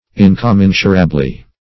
-- In`com*men"su*ra*ble*ness, n. -- In`com*men"su*ra*bly,
incommensurably.mp3